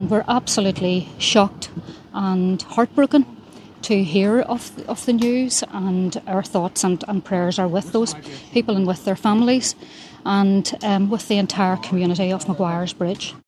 Fermanagh South Tyrone MP Pat Cullen says the community has been left shaken by the incident………….